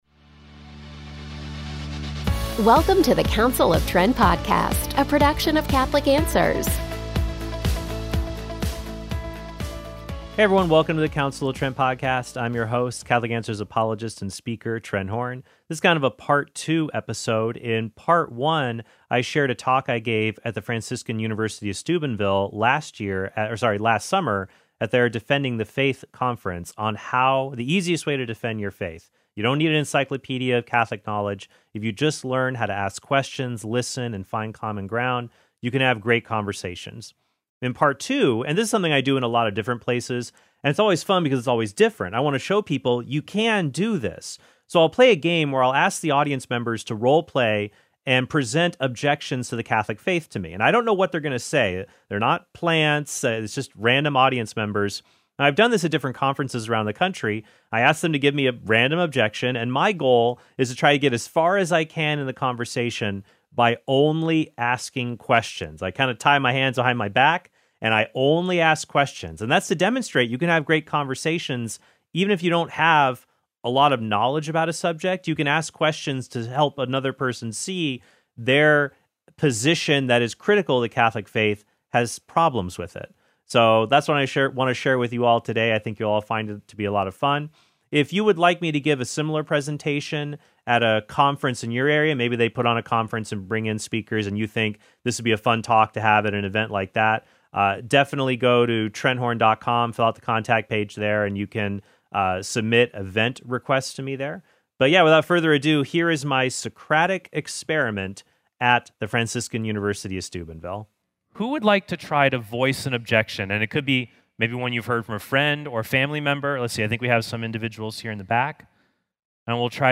So I’ll play a game where I’ll ask the audience members to role play and present objections to the Catholic faith to me.
It’s just random audience members and I’ve done this at different conferences around the country.